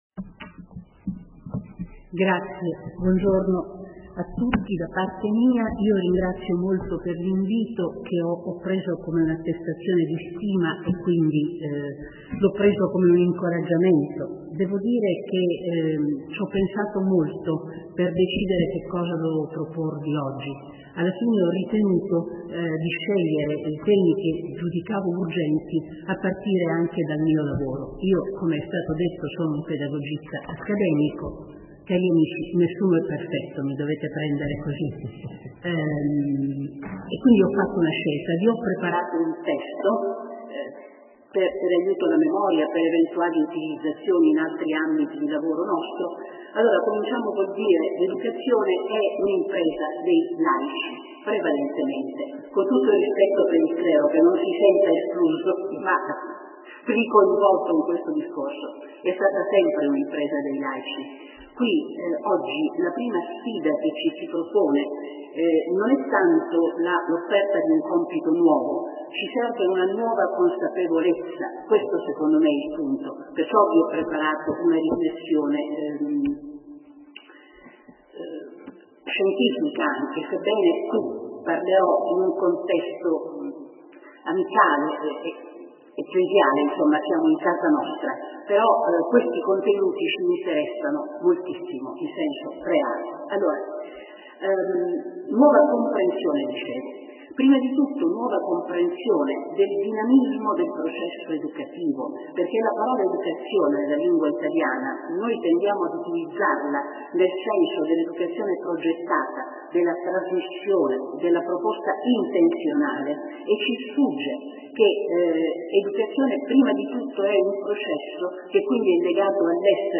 Assemblea Diocesana della Aggregazioni laicali